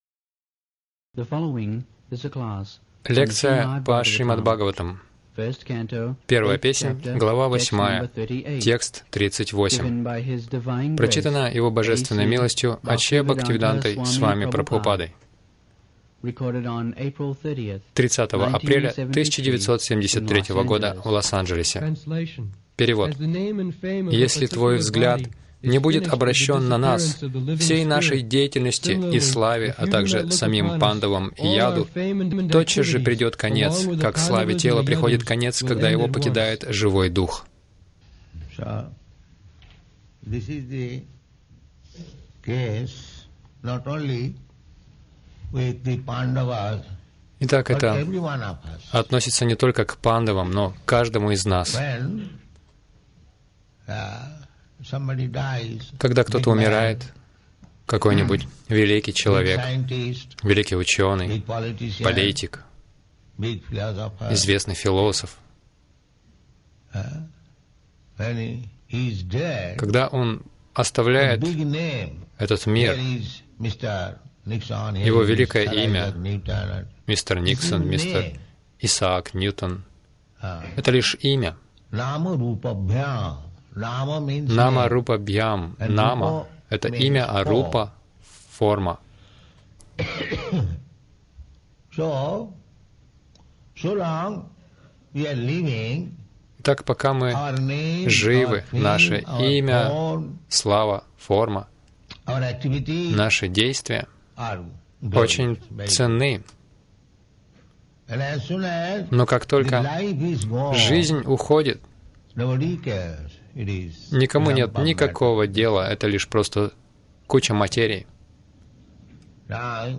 Милость Прабхупады Аудиолекции и книги 30.04.1973 Шримад Бхагаватам | Лос-Анджелес ШБ 01.08.38 — Направьте свои чувства на Кришну Загрузка...